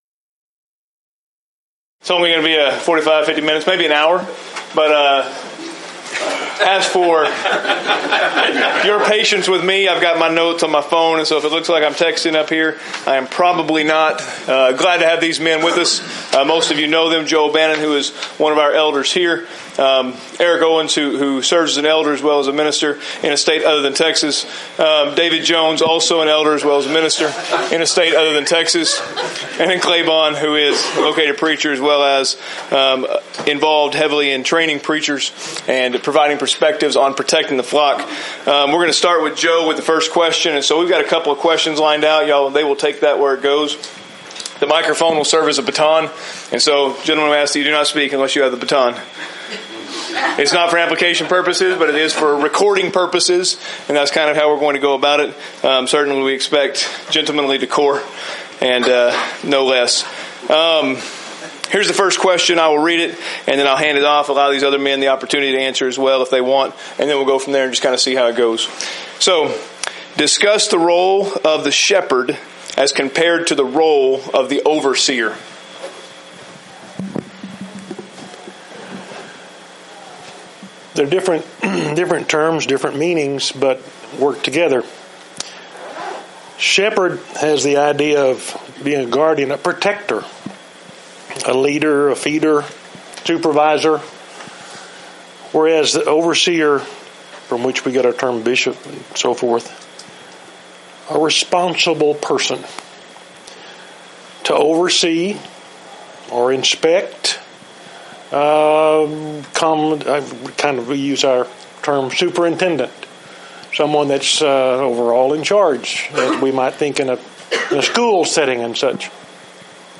Title: ELDERS: Forum - Protecting The Flock Speaker(s): Various